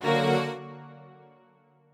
strings1_35.ogg